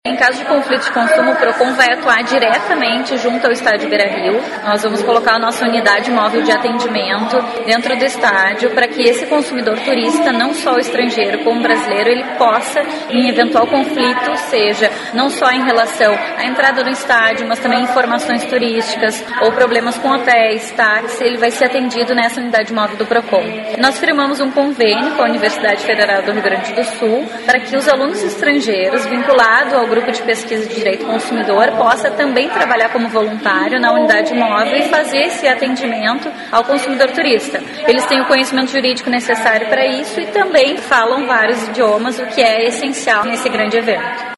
aqui e ouça fala na qual a diretora executiva do Procon de Porto Alegre, Flávia Pereira, explica como o órgão vai atuar.